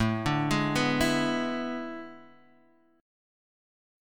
E7/A Chord